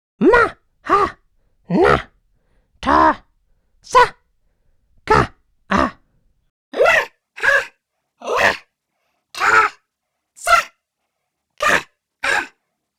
Character Vocals
The first part is the raw recording, and the second part is the final processed sound:
Mouse Example
all of the voices are pitch shifted, frequency shifted, and have chorus effects or even phasers on them.
BB_Mouse_VoiceExample_01.wav